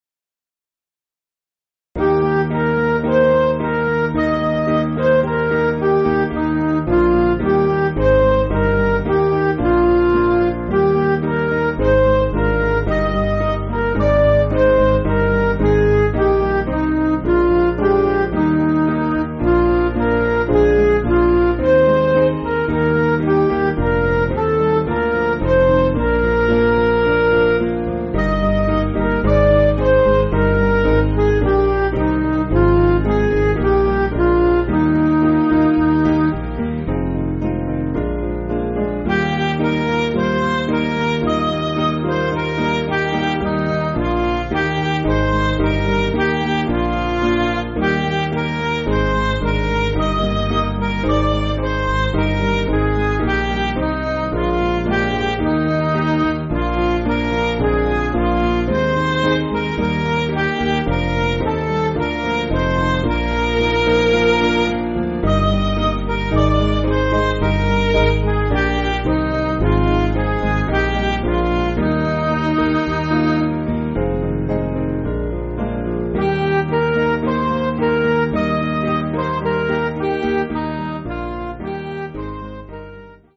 Piano & Instrumental
(CM)   6/Eb